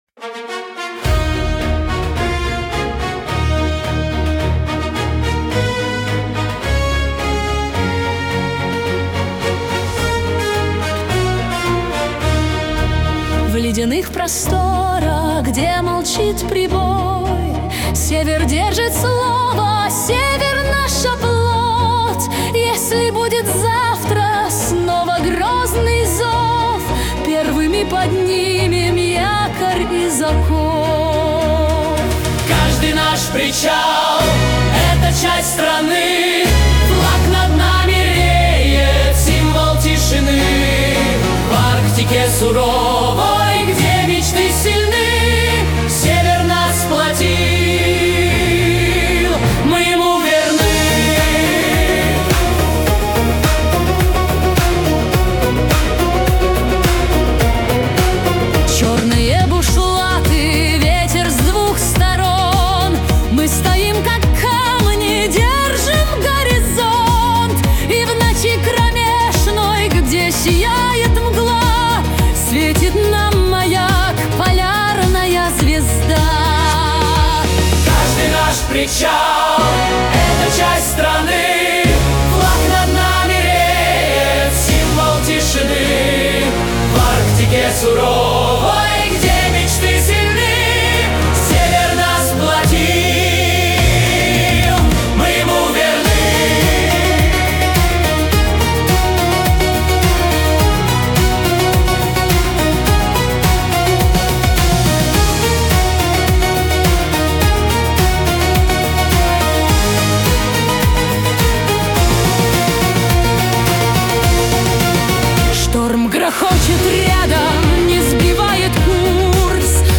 Исполнитель: Кавер-группа Future AI